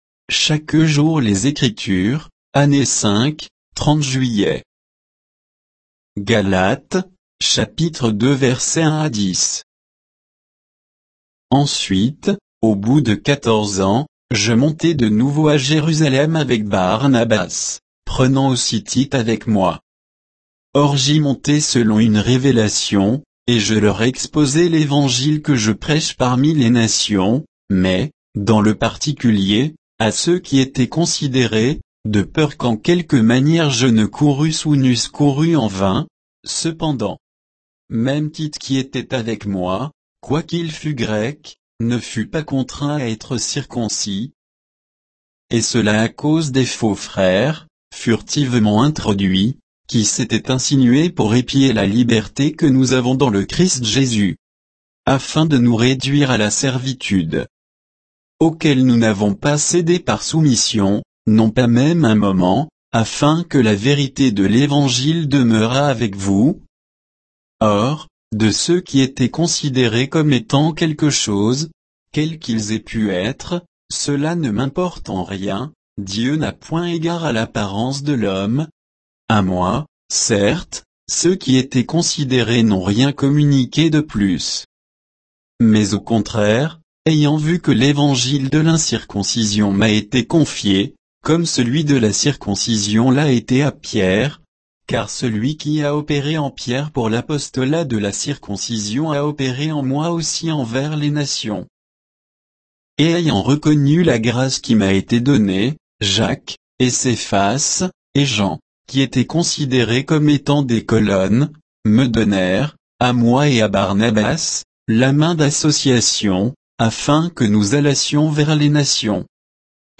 Méditation quoditienne de Chaque jour les Écritures sur Galates 2